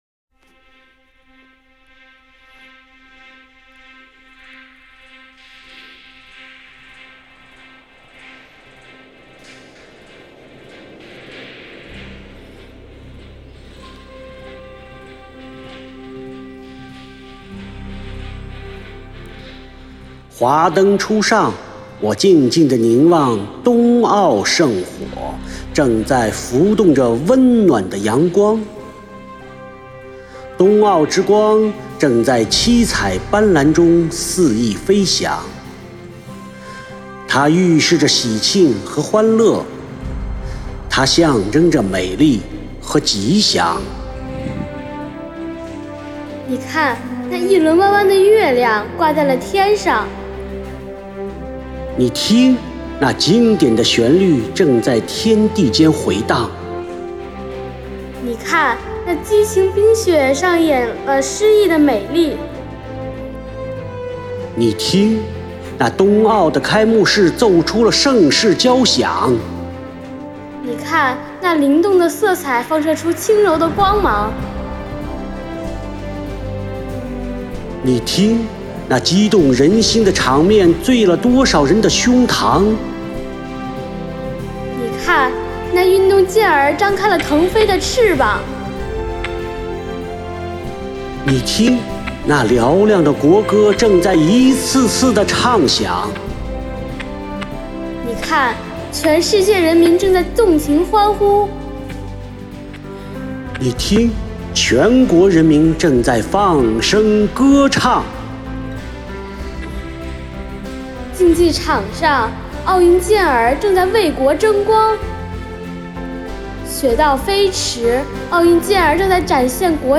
《冬奥之光》，老与小的组合，正是冬奥之光的传承与希望！